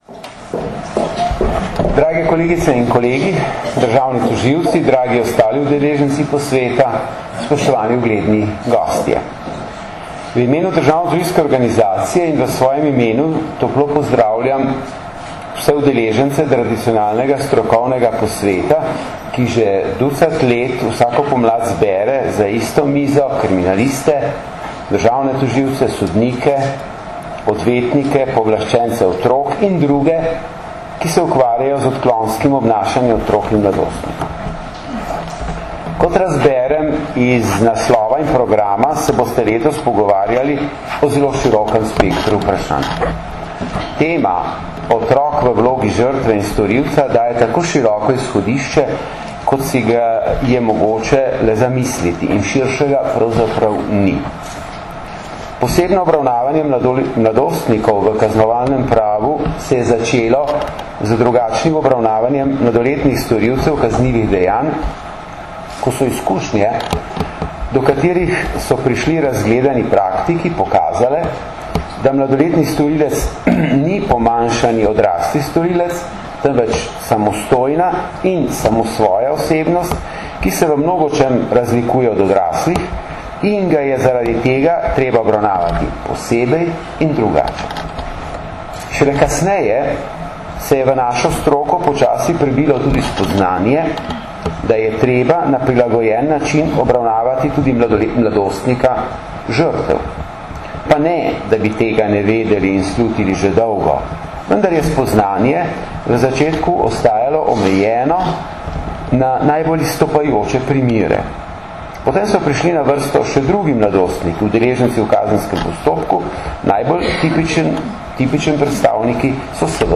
Na Brdu pri Kranju se je danes, 4. aprila 2013, začel dvodnevni strokovni posvet z naslovom Otrok v vlogi žrtve in storilca, ki ga že dvanajsto leto zapored organizirata Generalna policijska uprava in Društvo državnih tožilcev Slovenije v sodelovanju s Centrom za izobraževanje v pravosodju.
Zvočni posnetek nagovora generalnega državnega tožilca dr. Zvonka Fišerja (mp3)